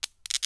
auto_idle_sparks3.wav